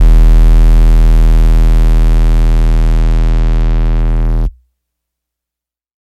Mamacita 808 - E.wav